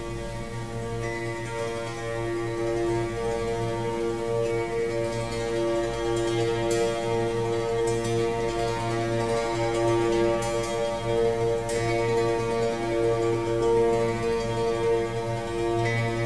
Das Gemisch aus 20 nahezu gleich gestimmten Saiten mit leicht gegeneinander verstimmten Obertonreihen erzeugt eine bunte Vielfalt von Summen- und Differenzfrequenzen, die über den Klangkörper miteinander gekoppelt sind und permanent Energie untereinander austauschen.
Im Bereich von 1,5 bis 2,5 kHz sowie etwa bei 500 Hz lassen sich wellenförmige Bewegungen in der Lautstärke einzelner Obertöne beobachten.
Abb. 18: Der Abstand der Obertöne voneinander nimmt nach oben hin zu.
monochord-mitte-007-b-mono.wav